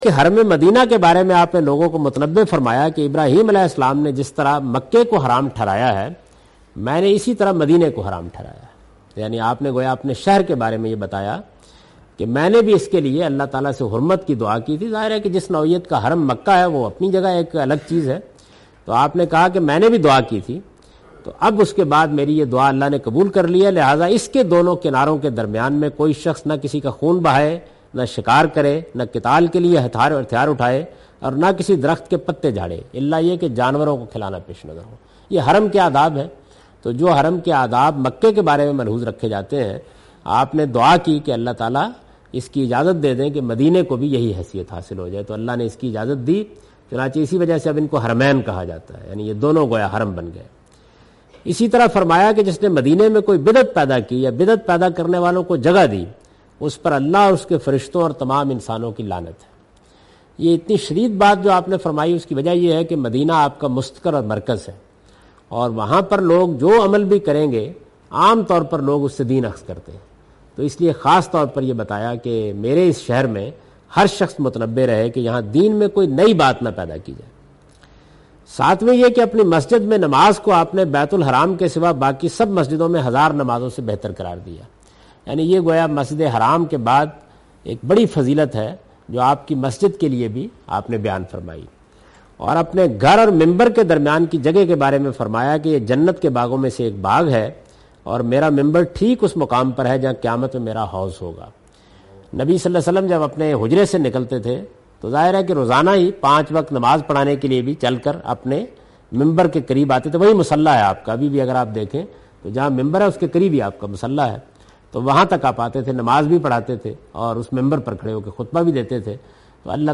In this video of Hajj and Umrah, Javed Ahmed Ghamdi is talking about "Sanctity of Madina".